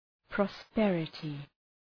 prosperity.mp3